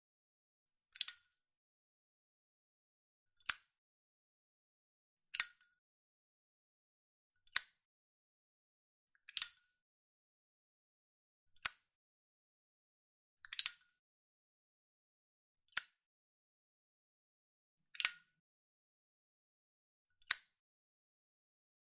Âm Thanh Tiếng Đóng, Bật Nắp Zippo